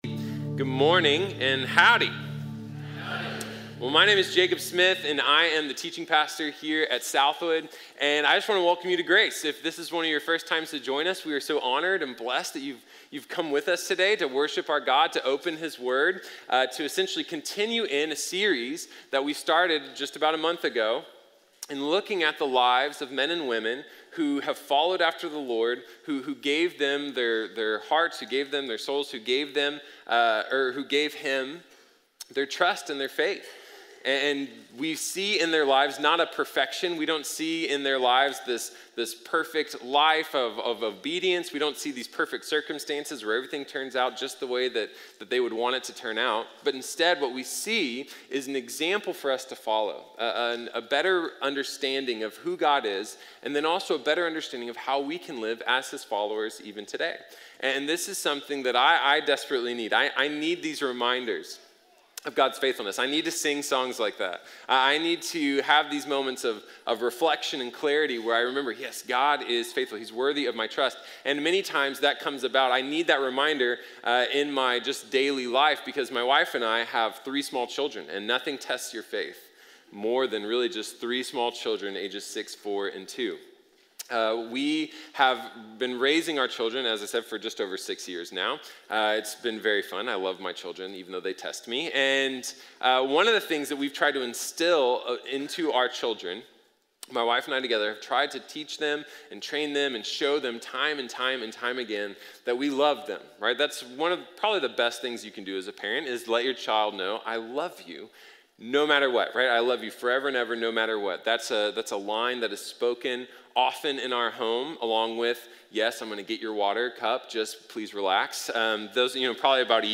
Isaac | Sermon | Grace Bible Church